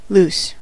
loose: Wikimedia Commons US English Pronunciations
En-us-loose.WAV